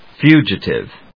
音節fu・gi・tive 発音記号・読み方
/fjúːdʒəṭɪv(米国英語), ˈfju:dʒʌtɪv(英国英語)/